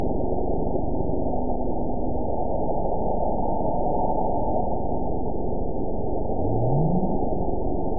event 912161 date 03/19/22 time 17:09:26 GMT (3 years, 2 months ago) score 9.65 location TSS-AB01 detected by nrw target species NRW annotations +NRW Spectrogram: Frequency (kHz) vs. Time (s) audio not available .wav